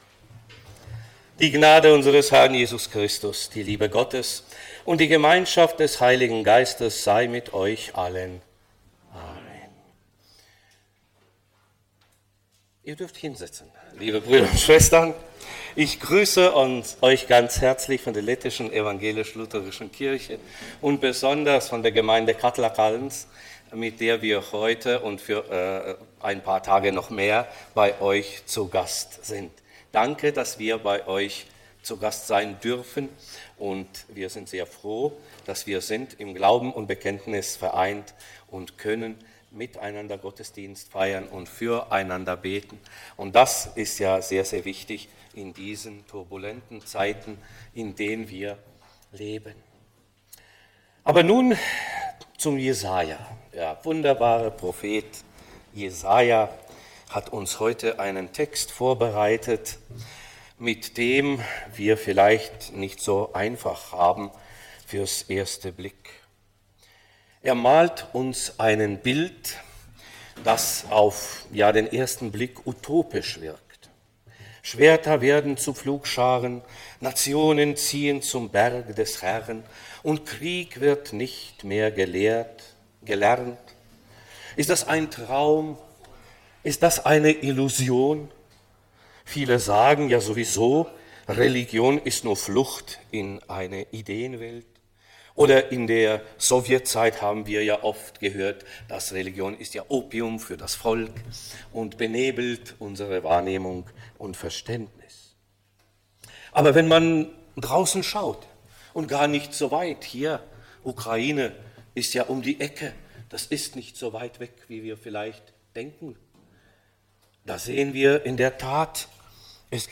Predigten der SELK Weigersdorf